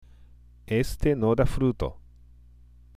（エステ　ノダ　フルート）